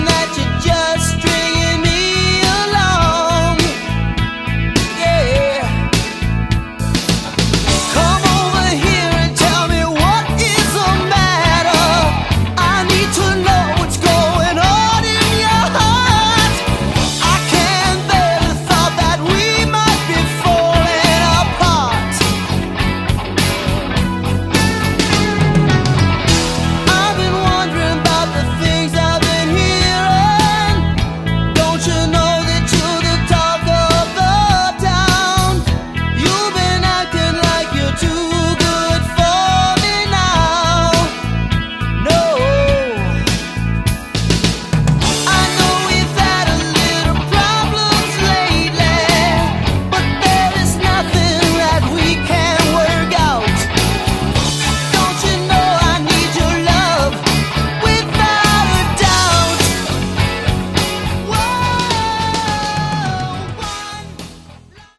Category: AOR
vocals
guitars, vocals
keyboards, vocals
bass
drums